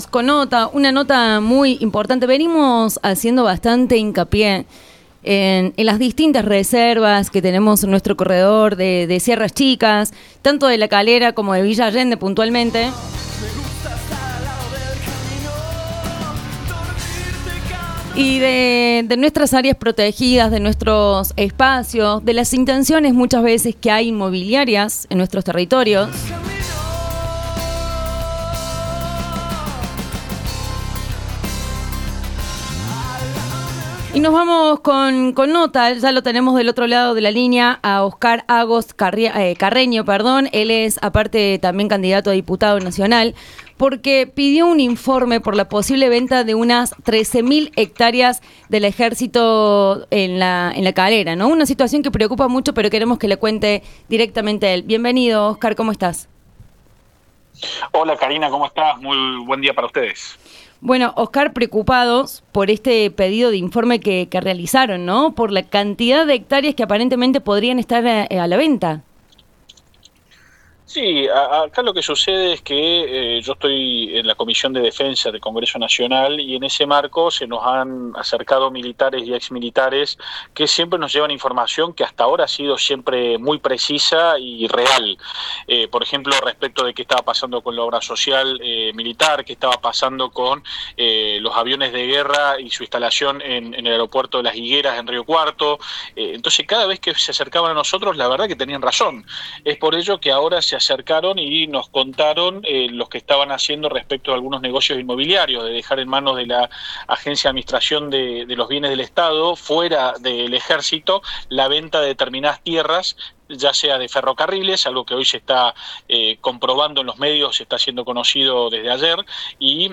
ESCUCHA LA NOTA COMPLETA DE AGOST CARREÑO EN ANEXADOS